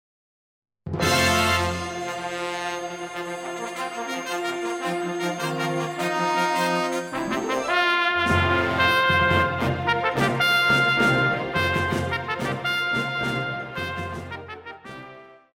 Pop
Trumpet
Band
Instrumental
World Music,Fusion
Only backing